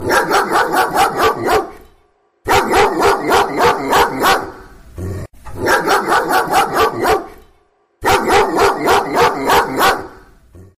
Kategori Dyr